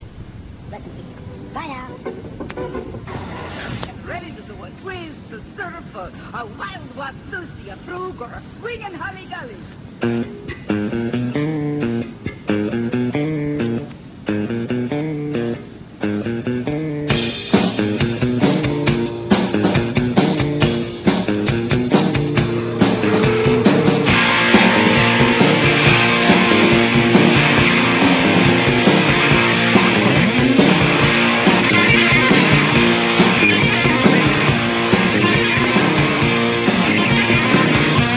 Here are some sound samples from the June/July sessions: